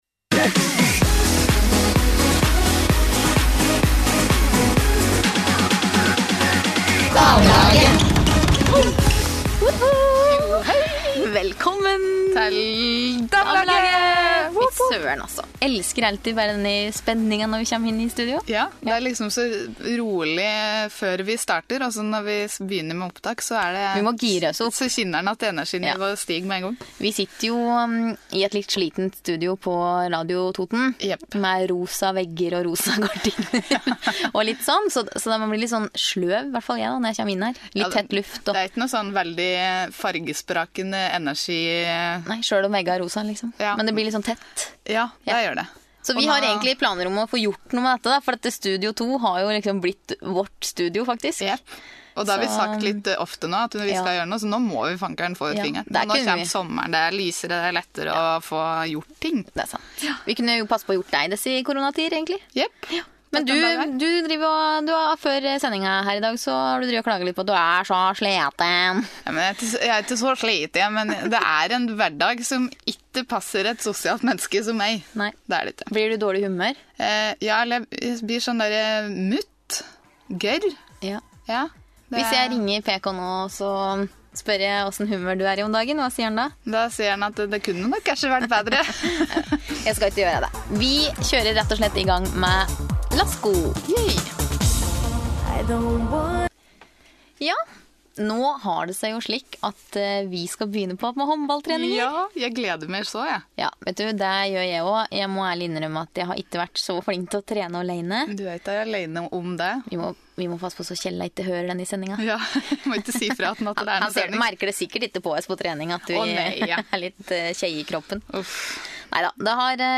Fortvil itte, vi kom øss inn i studio 2 læll :) | Radio Toten
Uten musikk:
Damelaget-uke-20_nettet-uten-musikk.mp3